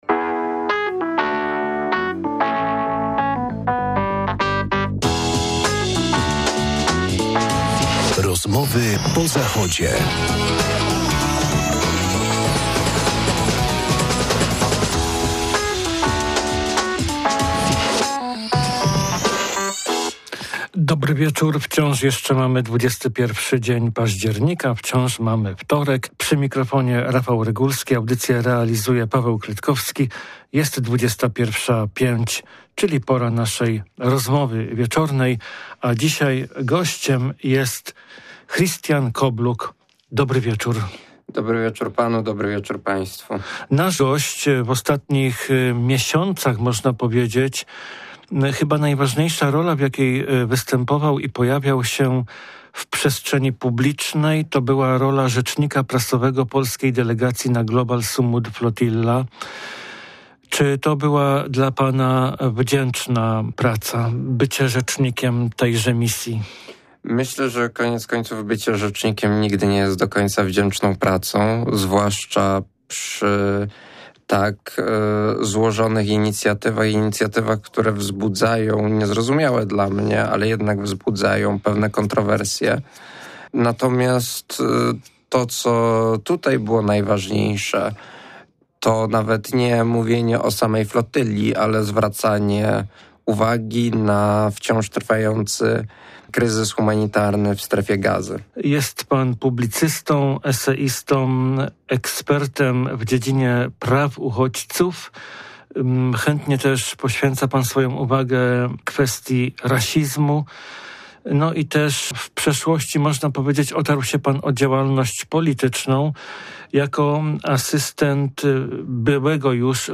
Jak wiemy ten międzynarodowy cywilny konwój morski nie dotarł do celu. Czy oznacza to porażkę? Między innymi o tym opowiada gość audycji, na co dzień publicysta, podejmujący tematykę uchodźców i rasizmu.